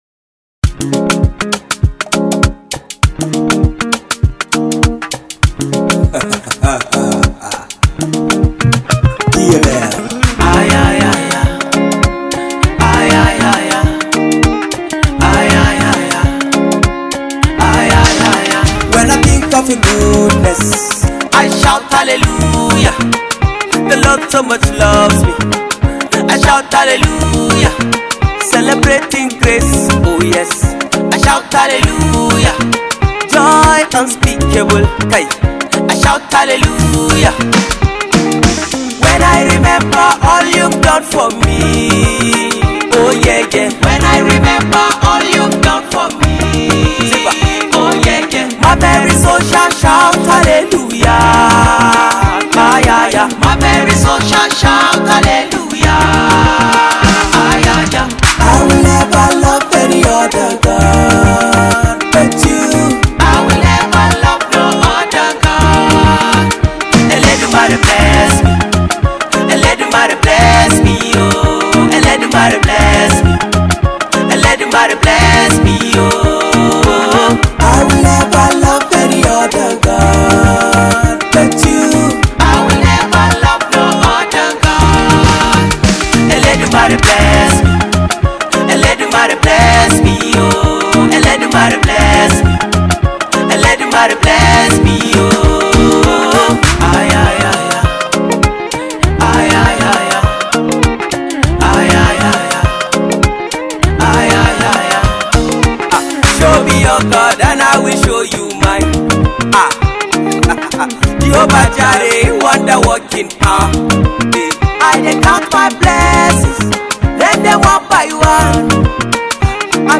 afro groovy, awesome song